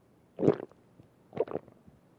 Звук глотания человека: хруст расслоения